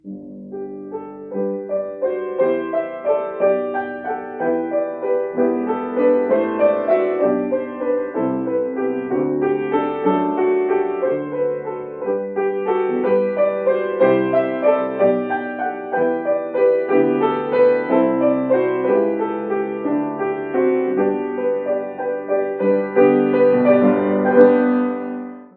Poniższy wpis zawiera zestawienie 10 wybitnych utworów muzyki poważnej w uproszczonych aranżacjach dla średniozaawansowanych pianistów.
Bach-Jesu-Joy-of-Mans-Desiring-Piano-with-Sheet-videoo.info_.mp3